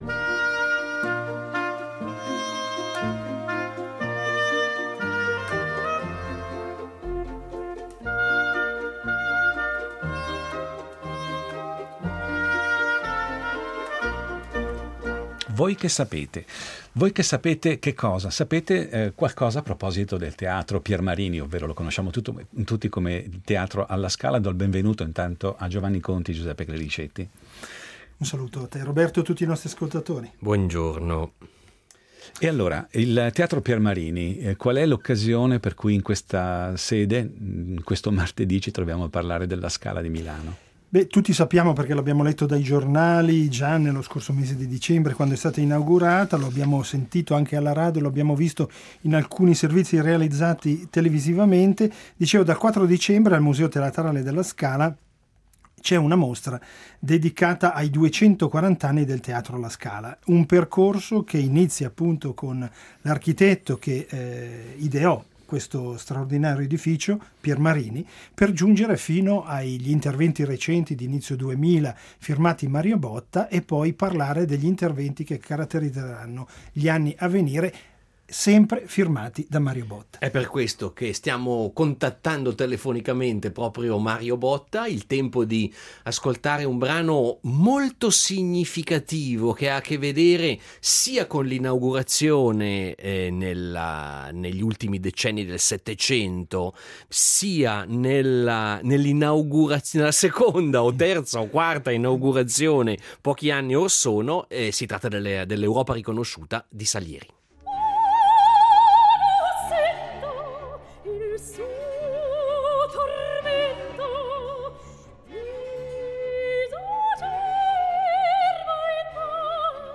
Il Museo Teatrale alla Scala ospita una mostra dedicata ai 240 anni di storia architettonica del Teatro. Questo il pretesto per essere a colloquio con l’architetto svizzero Mario Botta e ripercorrere una vicenda che va da Piermarini a Sanquirico, da Secchi a Botta attraversando la storia della Scala che si intreccia con quella della Città e del Paese, riflettendo mutamenti sociali e trasformazioni tecnologiche, fino alla costruzione del nuovo edificio che sorgerà in via Verdi nei prossimi anni.